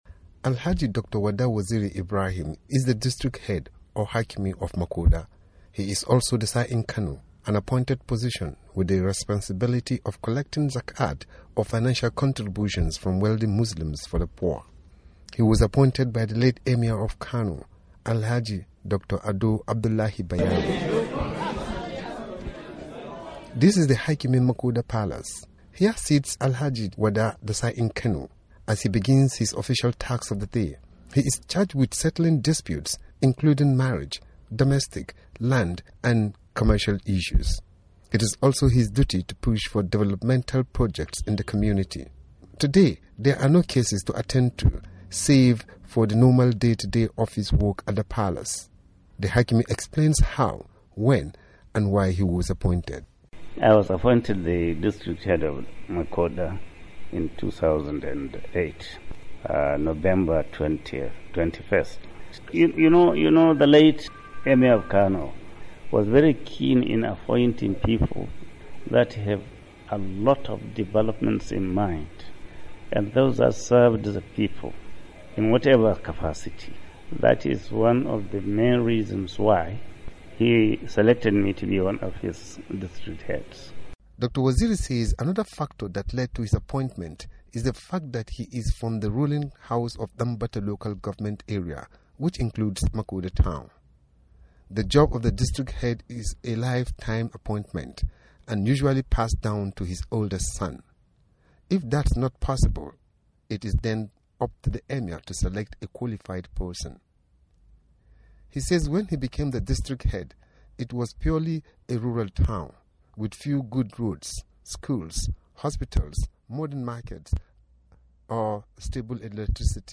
"I tried the best I could ...." says the hakimi of Makoda